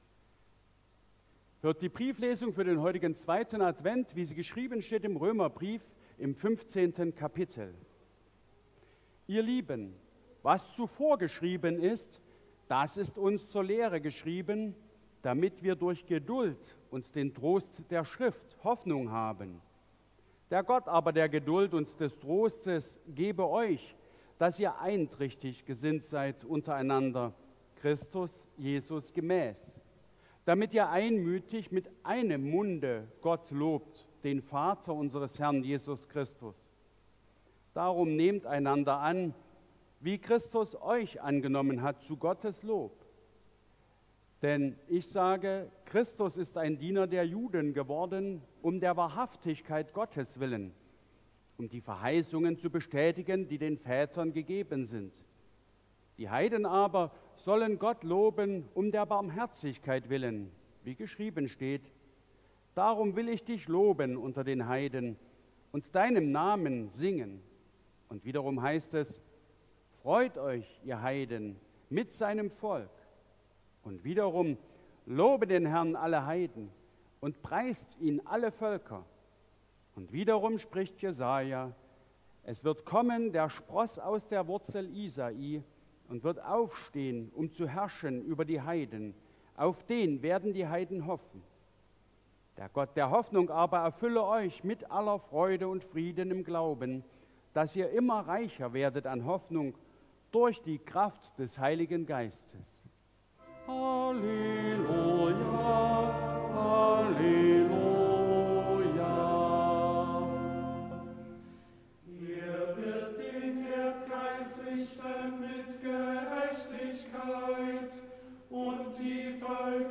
Brieflesung aus Römer 15,4-13 Ev.-Luth.
Audiomitschnitt unseres Gottesdienstes vom 1.Avent 2024